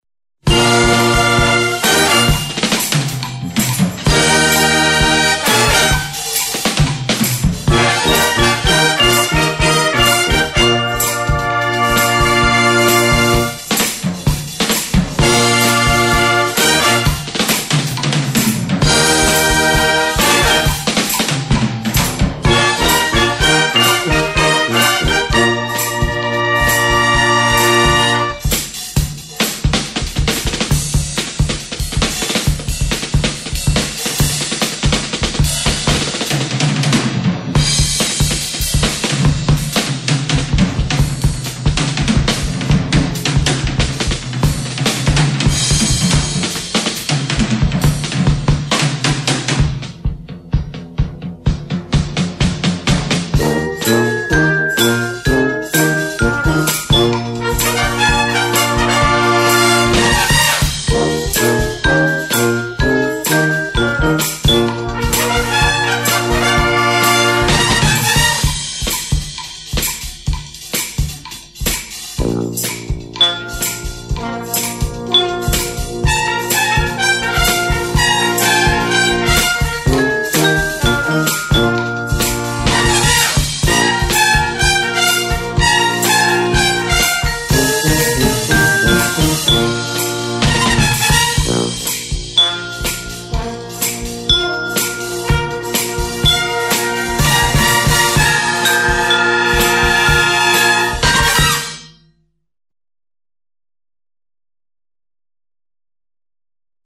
enregistré en 2000 à Gardonne
dans la salle Jeanne d'Arc
depuis un fourgon devant la salle